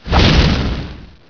staffswing_3.wav